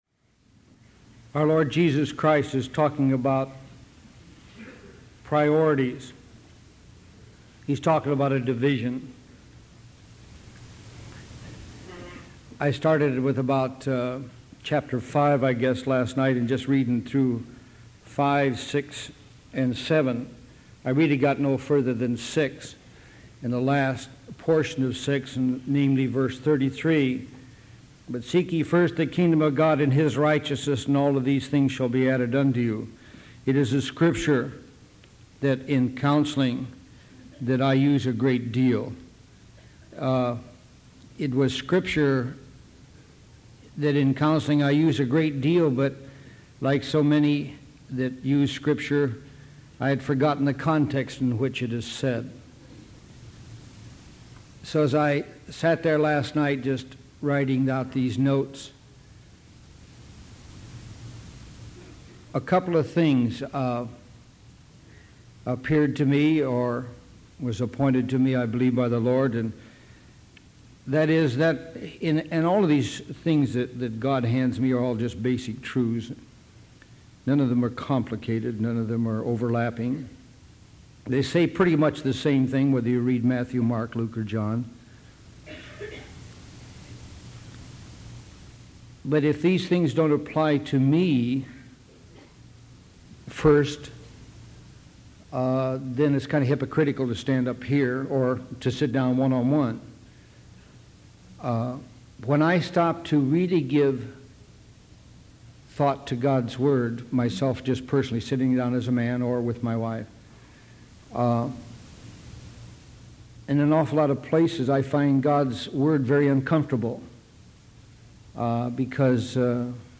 Priorities download sermon mp3 download sermon notes Welcome to Calvary Chapel Knoxville!